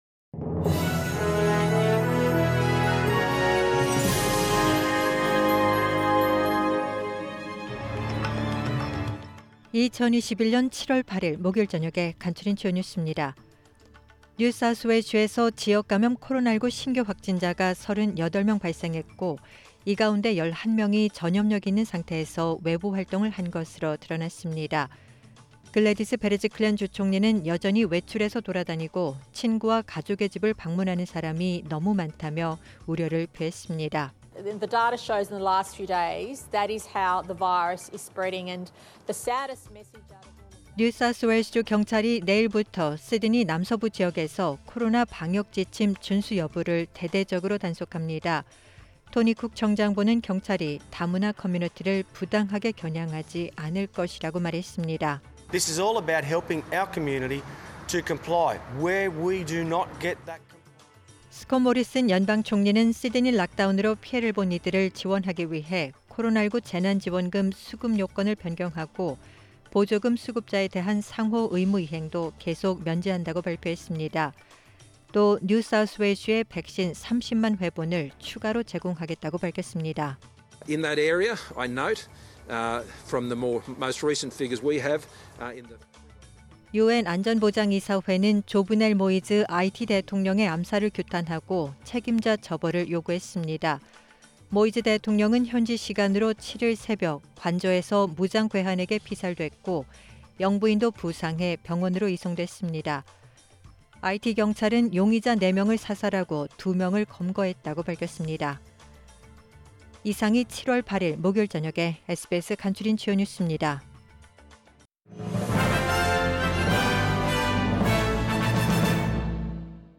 SBS News Outlines...2021년 7월 8일 저녁 주요 뉴스